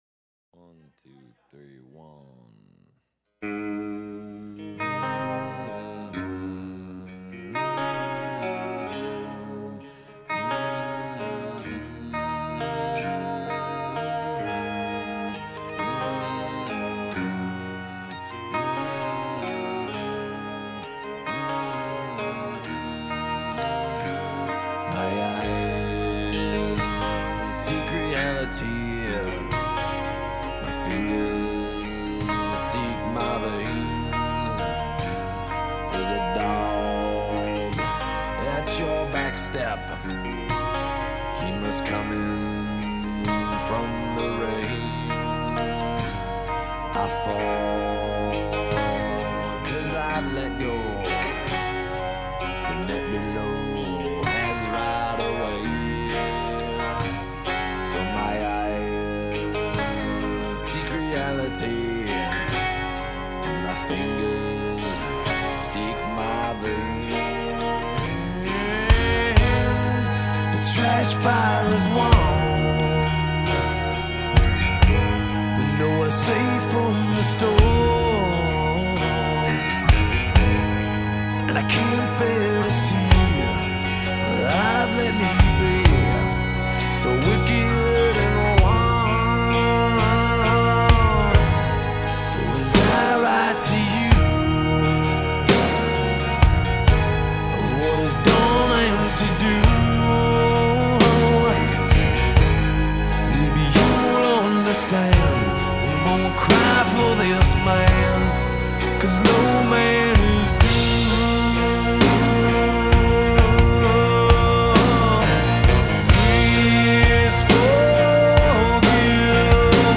исполняющая музыку в стилях трэш-метал и хэви-метал